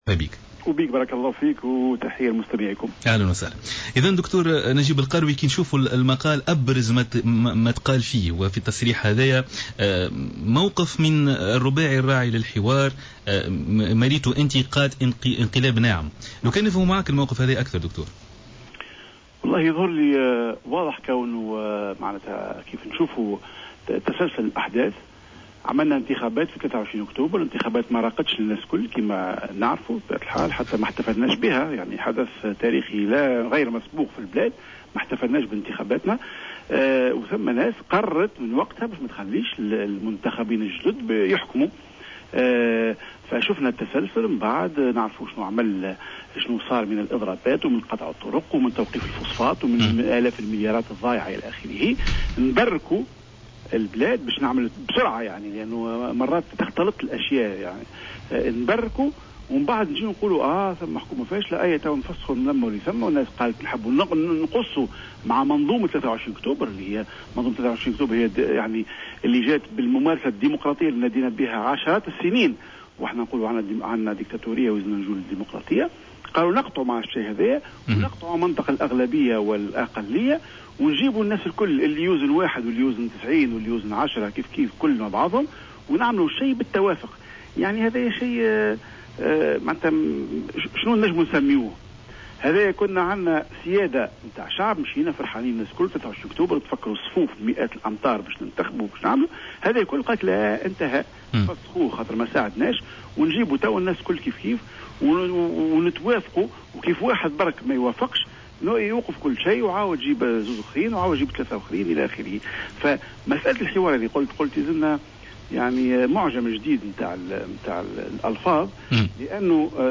تصريح لجوهرة أف أم